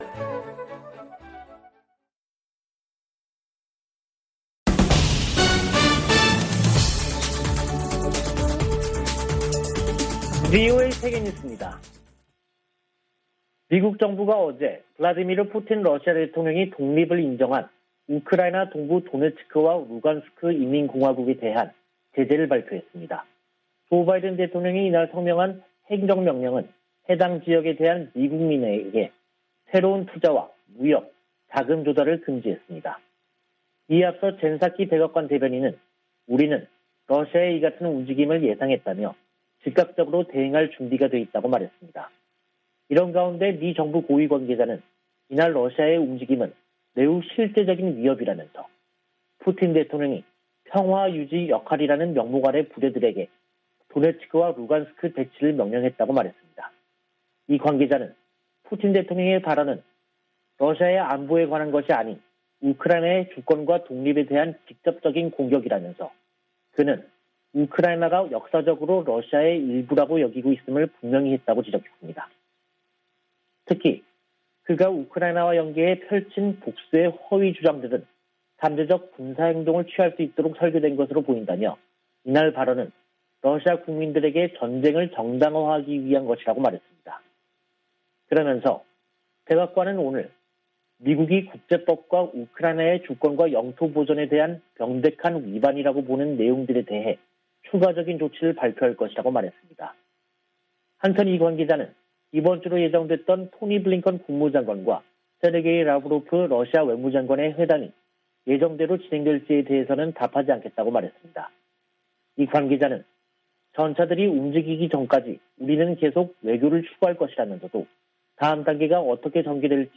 VOA 한국어 간판 뉴스 프로그램 '뉴스 투데이', 2022년 2월 22일 2부 방송입니다. 토니 블링컨 미 국무장관이 왕이 중국 외교부장과 북한 문제와 우크라이나 사태 등에 관해 전화협의했습니다. 조 바이든 미국 행정부가 러시아 군의 우크라이나 침공에 대응하는 조치를 구체화하는 가운데 한국 등 아시아 동맹국의 인도주의 지원 등이 거론되고 있습니다. 김정은 북한 국무위원장이 시진핑 중국 국가주석에게 친서를 보내 대미 공동전선 협력을 강조했습니다.